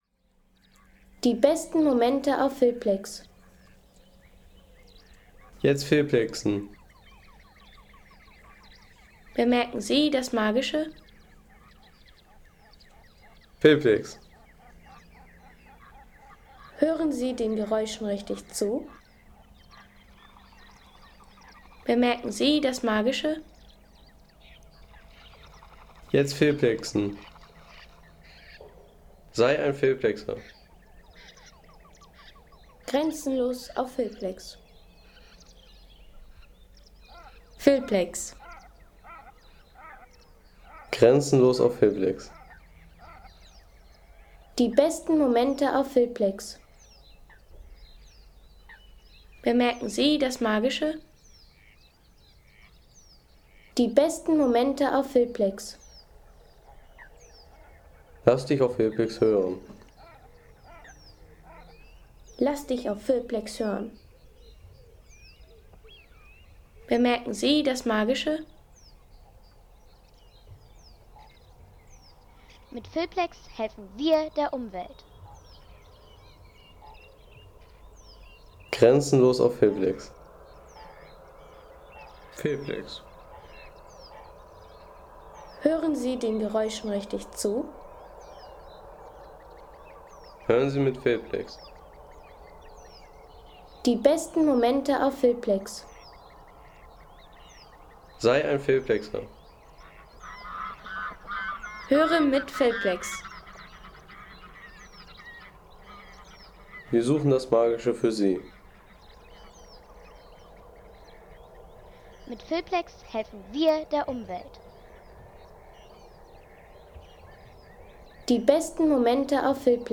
Winderatter See Home Sounds Landschaft Bäche/Seen Winderatter See Seien Sie der Erste, der dieses Produkt bewertet Artikelnummer: 211 Kategorien: Landschaft - Bäche/Seen Winderatter See Lade Sound.... Erlebe die bezaubernde Klangwelt Schleswig-Holsteins – Ein Naturerle ... 3,50 € Inkl. 19% MwSt.